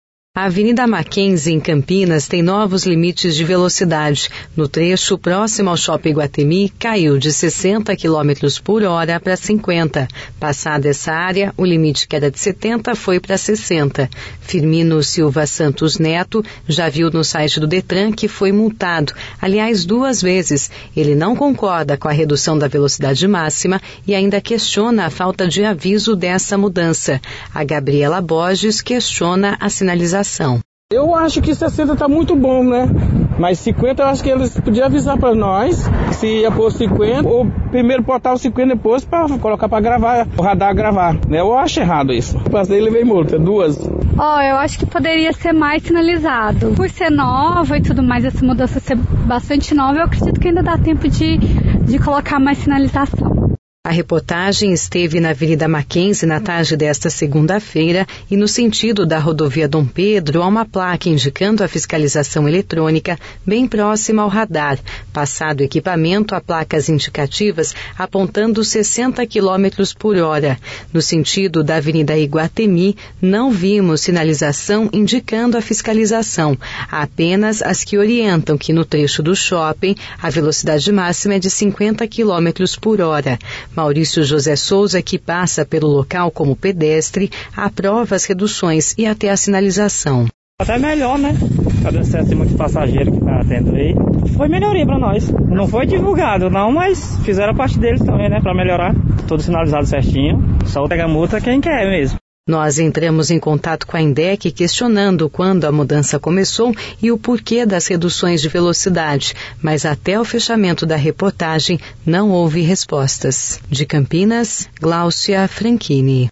A reportagem esteve na avenida Mackenzie na tarde desta segunda-feira (22/08) e no sentido da Rodovia Dom Pedro há  uma placa indicando a fiscalização eletrônica, bem próxima ao radar.